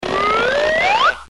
物品放上去的音效.MP3